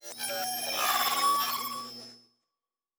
Data Calculating 5_4.wav